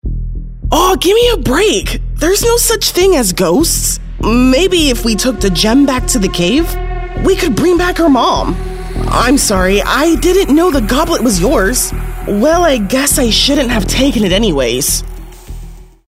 African American, animated, character, confident, conversational, cool, hard-sell, millennial, perky, storyteller, upbeat